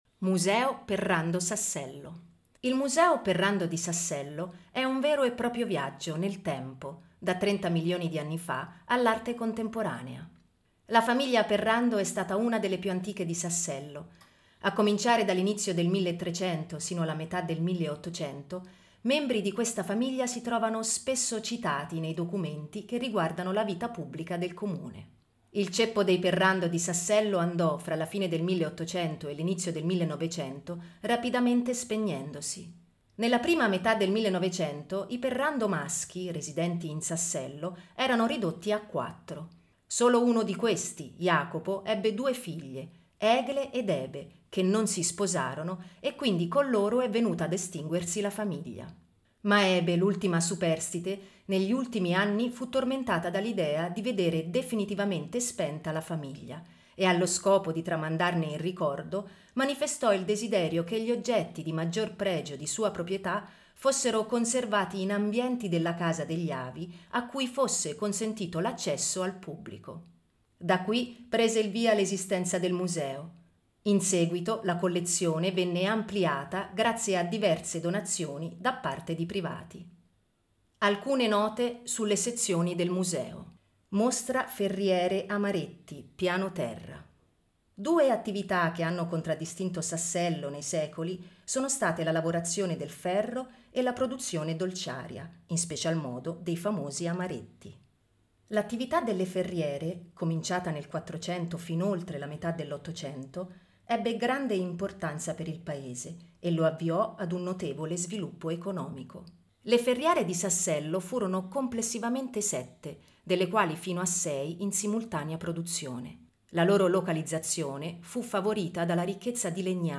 audioguida-sassello-museo-perrando.mp3